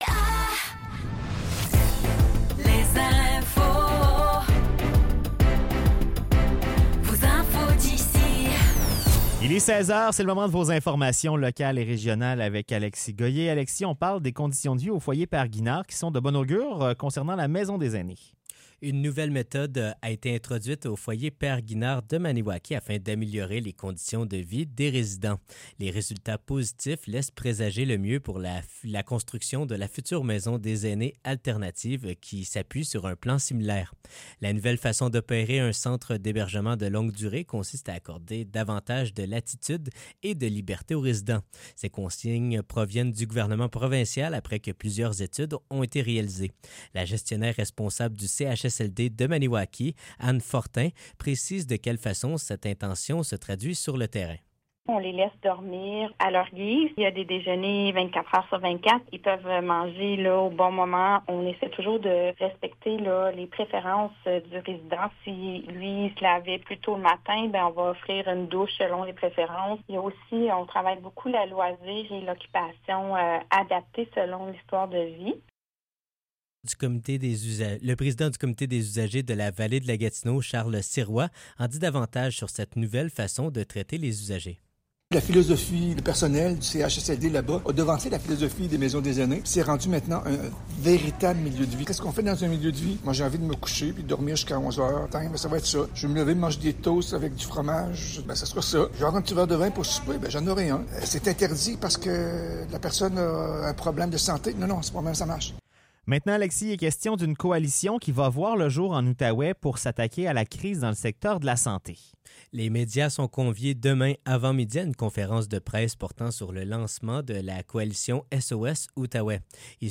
Nouvelles locales - 5 juin 2024 - 16 h